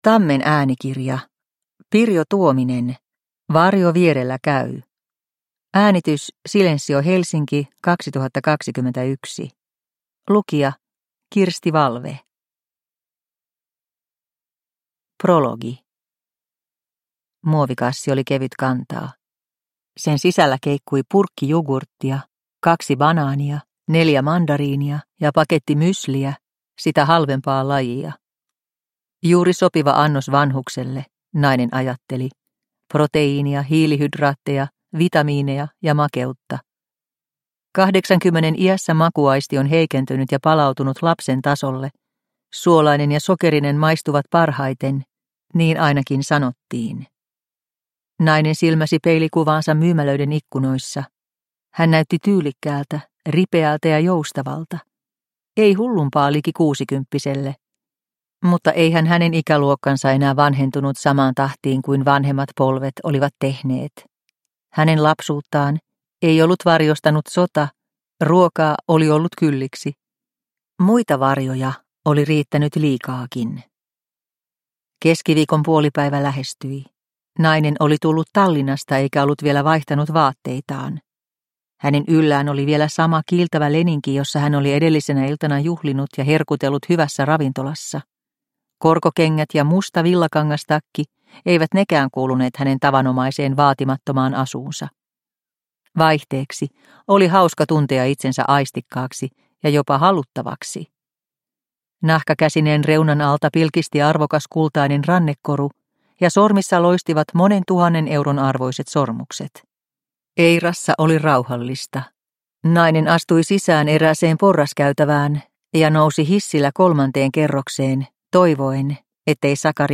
Varjo vierellä käy – Ljudbok – Laddas ner